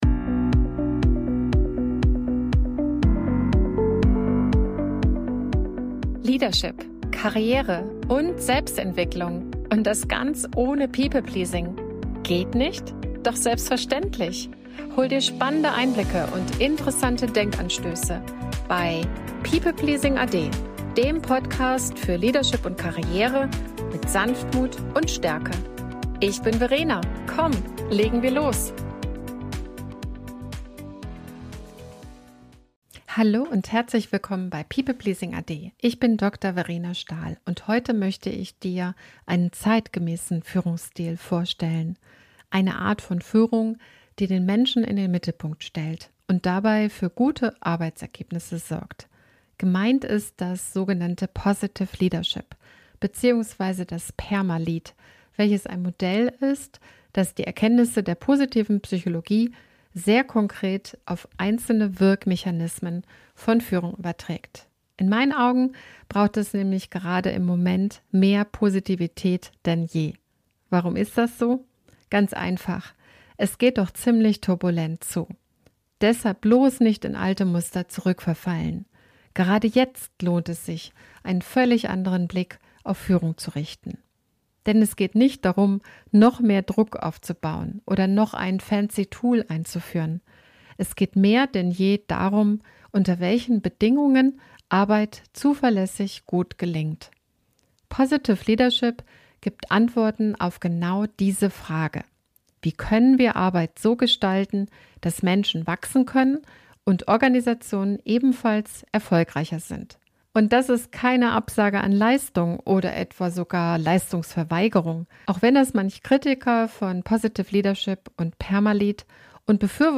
In dieser Solo-Folge stelle ich dir das PERMA-LEAD-Modell vor – ein wissenschaftlich fundiertes Konzept aus der Positiven Psychologie, das zeigt, wie Führungskräfte durch Stärkenorientierung und klare Routinen die Resilienz und Leistungsfähigkeit ihres Teams erhöhen können.